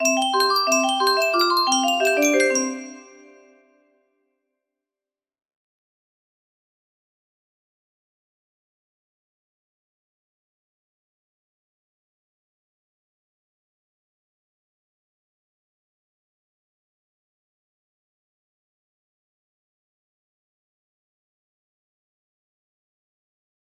pequeña serenata nocturna modelo clásico music box melody